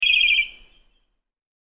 SMS Alert